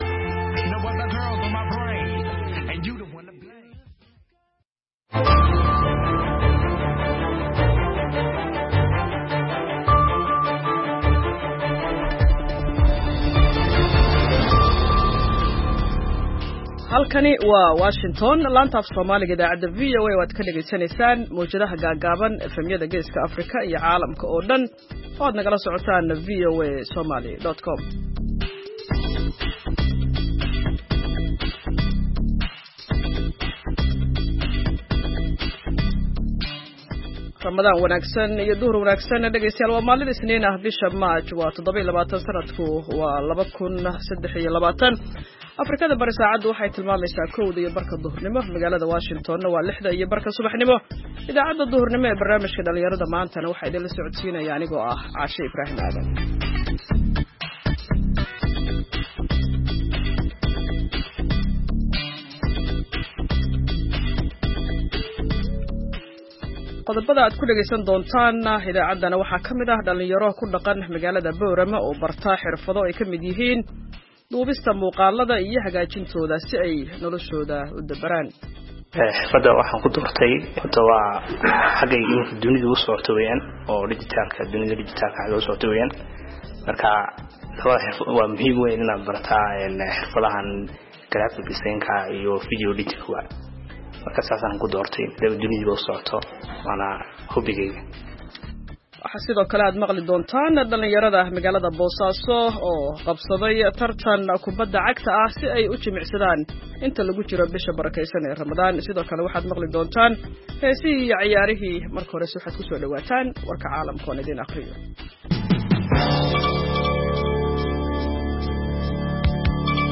Idaacadda Duhurnimo waxaad qeybta hore ku maqli kartaa wararka ugu waaweyn ee Soomaaliya iyo Caalamka. Qeybta danbe ee idaacaddu waxay idiin soo gudbinaysaa barnaamijyo ku saabsan dhalinyarada maanta.